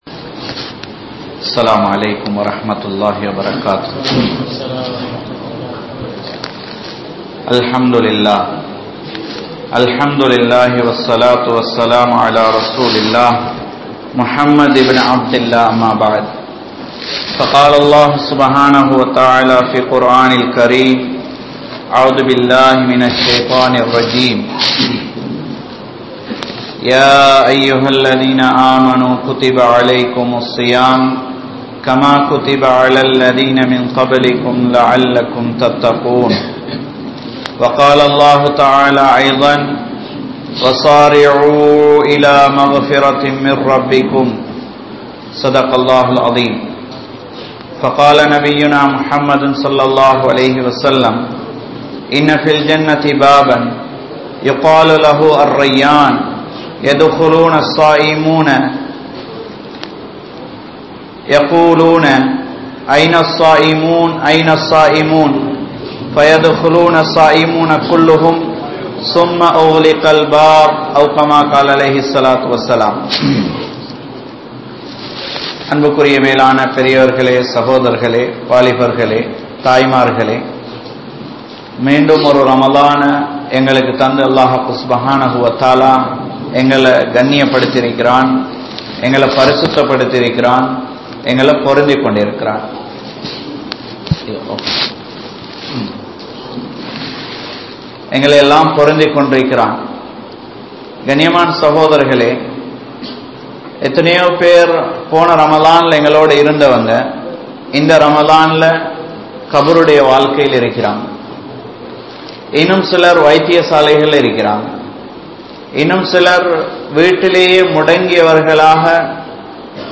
Paavaththoadu Vaalaatheerhal (பாவத்தோடு வாழாதீர்கள்) | Audio Bayans | All Ceylon Muslim Youth Community | Addalaichenai